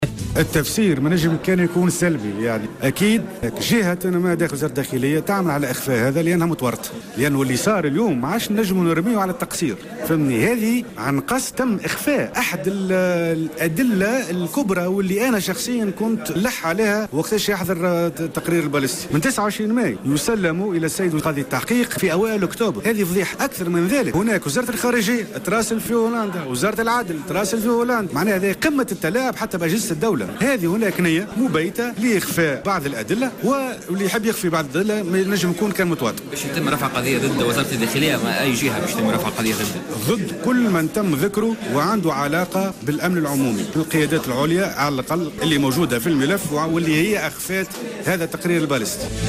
في تصريح ل"جوهرة أف أم" اليوم على هامش الندوة الصحفية التي عقدتها اللجنة